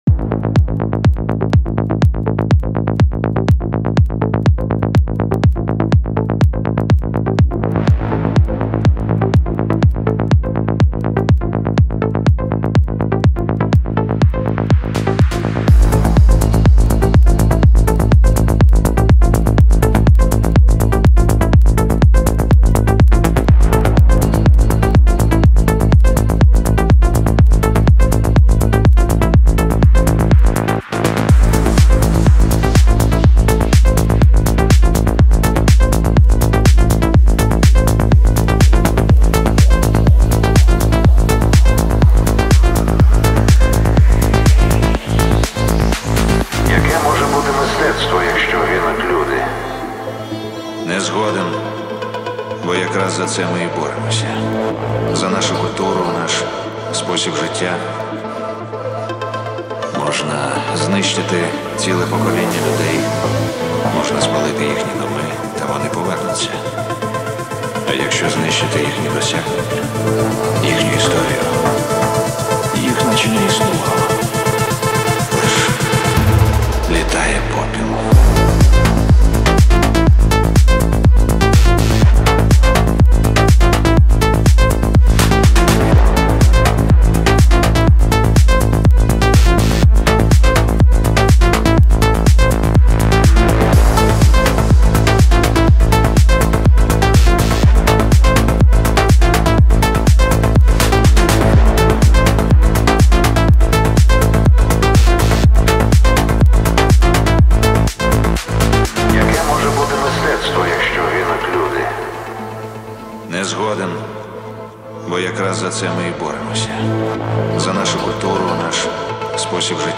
• Жанр: Techno, House